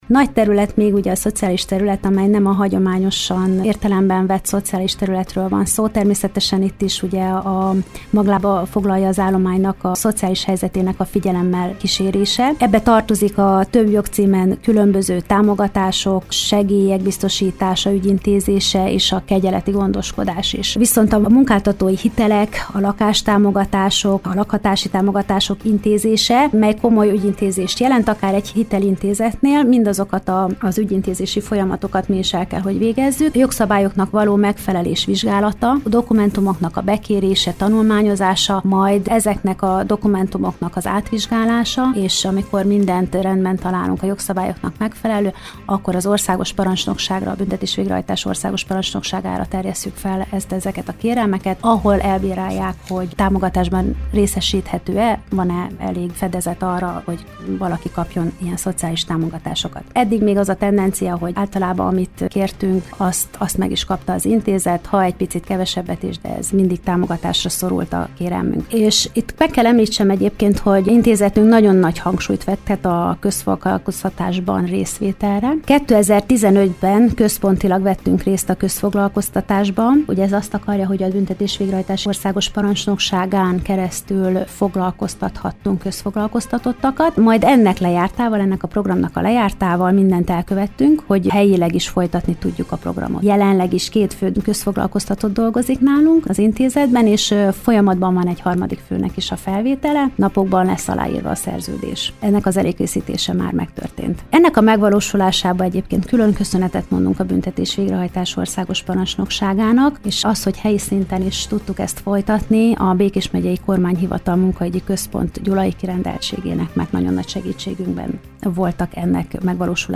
Vele beszélgetett tudósítónk a Személyügyi és Szociális Osztály tevékenységéről.